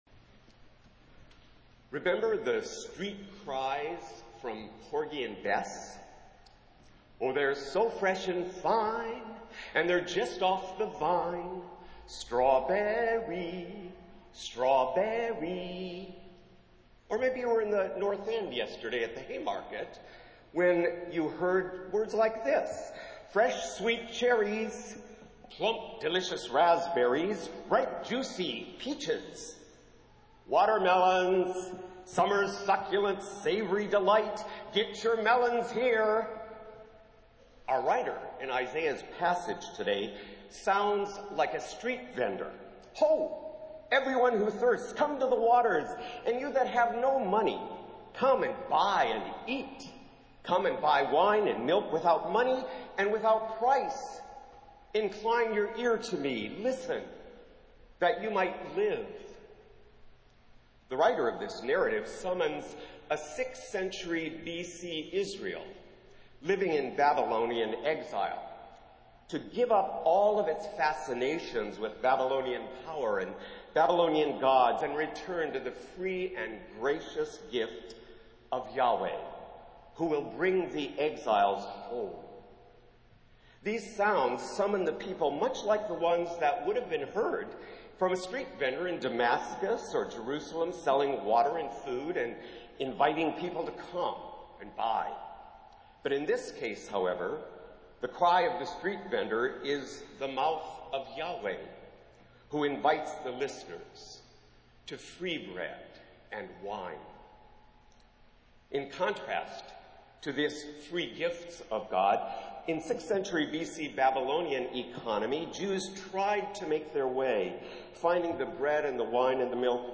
Festival Worship - Eighth Sunday after Pentecost